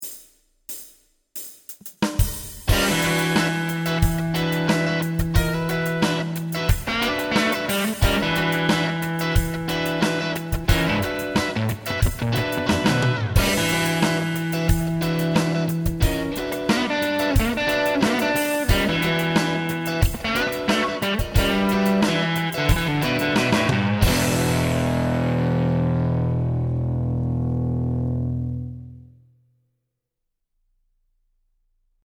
プリアンプ(LINE6 POD2)
HDR での処理は、レベル合わせのためのノーマライズと、軽いリバーブのみ。
Lace Music Alumitone Set Left: Neck+Bridge: Crunch
Right: Bridge: Lead
Left 前半: Center+Bridge: Crunch
Left 後半: Neck+Center: Crunch
Right: Neck: Lead